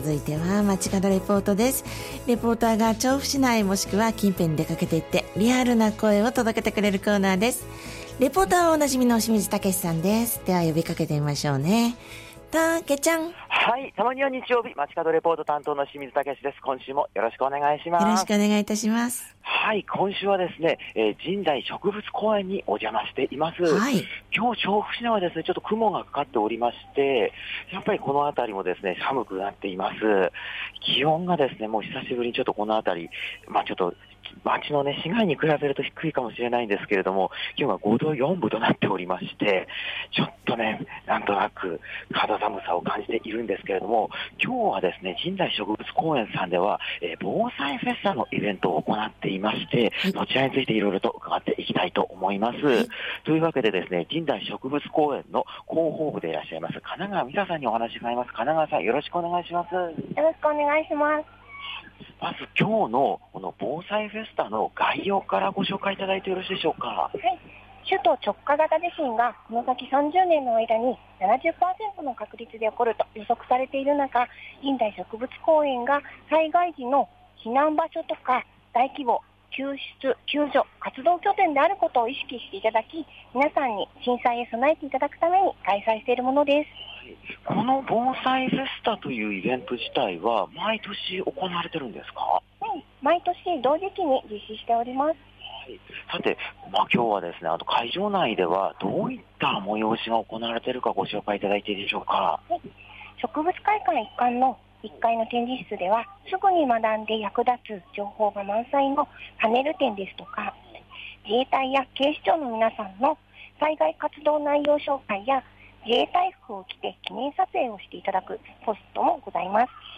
曇り空、真冬の気候の空の下からお届けした街角レポートは、 都立神代植物公園で開催中の「防災フェスタ」からお届けしました！